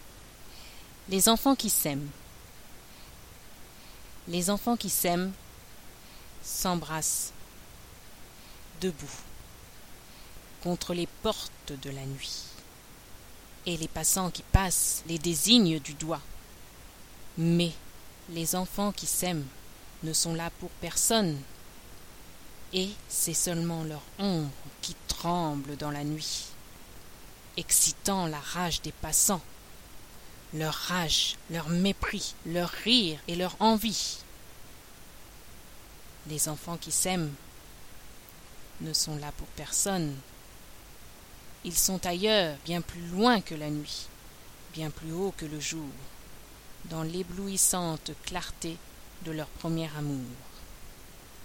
Audio proposé par l'atelier théâtre adulte du Centre Nelson Mandela